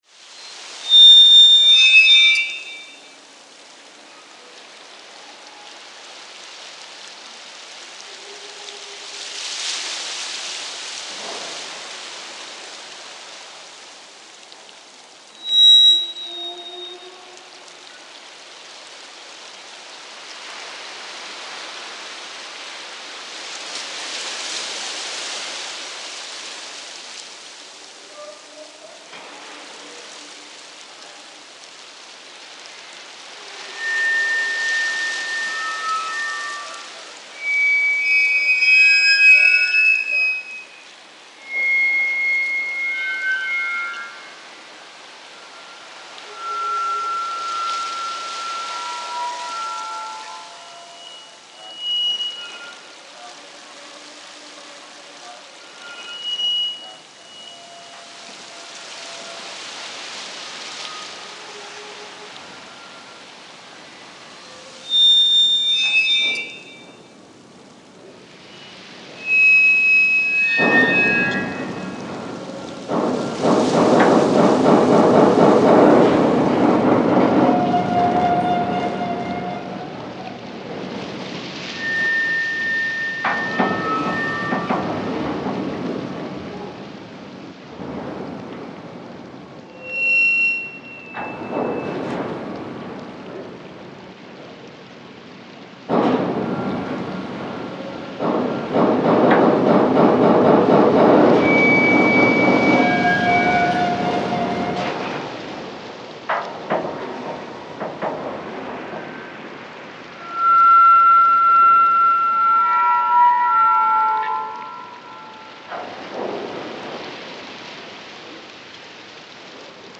A large mouth, two river banks with 2.6 million inhabitants, 100,000 of them crossing the river daily by ferry, like a pendulum. At the border between city and river, a spring of noise bursts.
lisbon.mp3